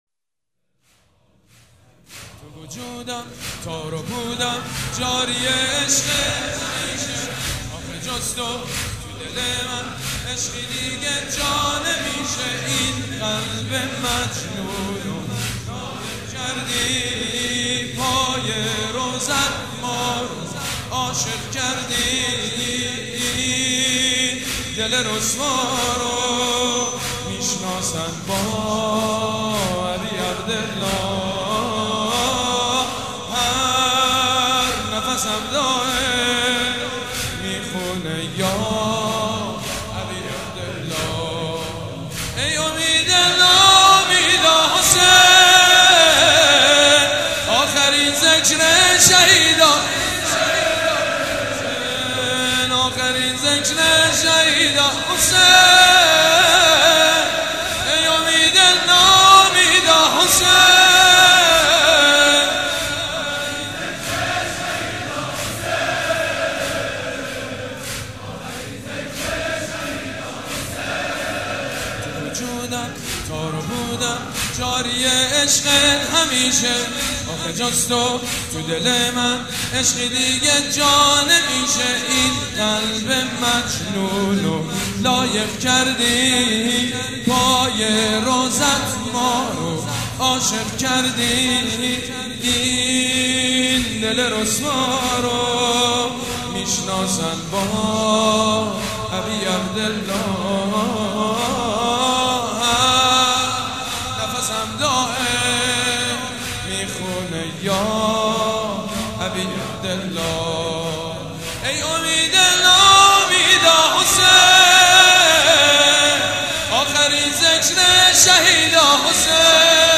واحد | تو وجودم تار و پودم جاری عشقه همیشه
مداحی حاج سید مجید بنی فاطمه | شب اول محرم 1396 | هیأت ریحانه الحسین(س)